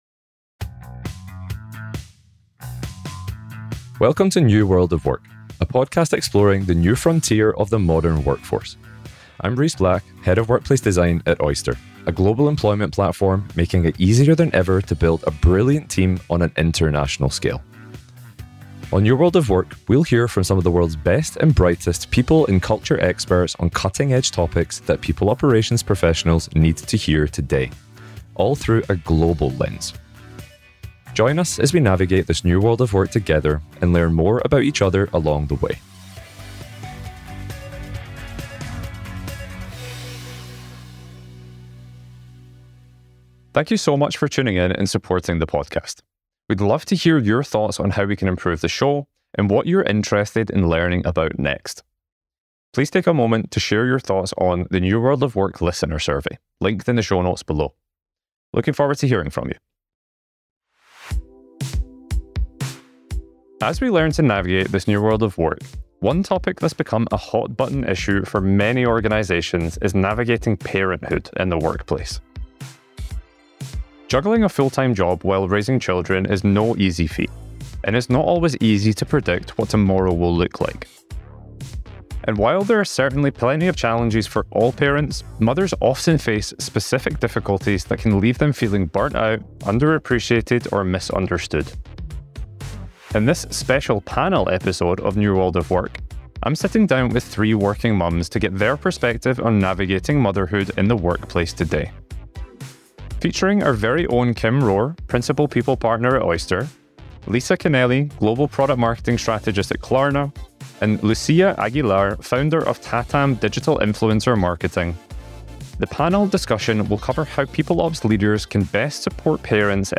Panel: Experts share their tips for supporting parents in the workplace